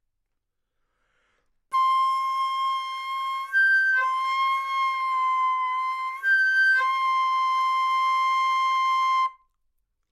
长笛单音（吹得不好） " 长笛 C6 badtimbre
描述：在巴塞罗那Universitat Pompeu Fabra音乐技术集团的goodsounds.org项目的背景下录制。